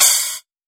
Techno Cymbal 02.wav